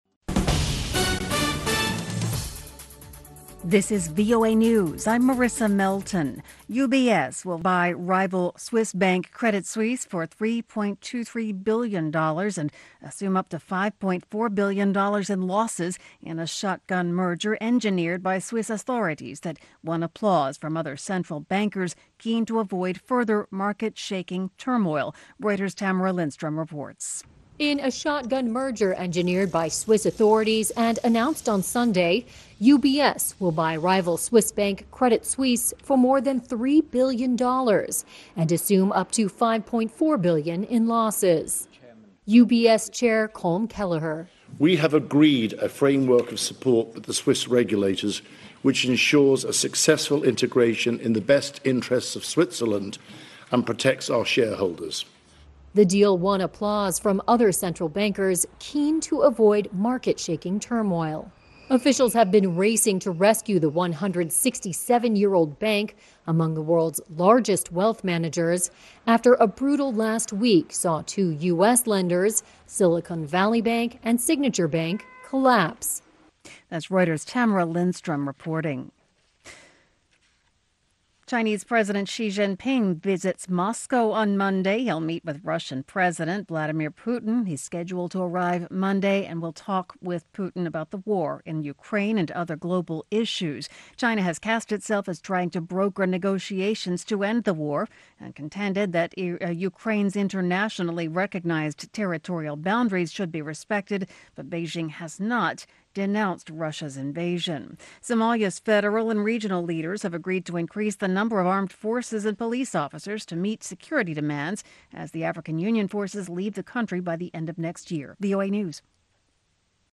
VOA Newsに掲載されたニュース音声・スクリプト・テストを使用したリスニング・リーディングのトレーニングに最適な教材を用意しました。